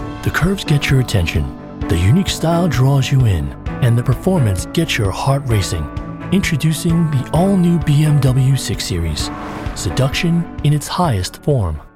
Sophisticated/Luxury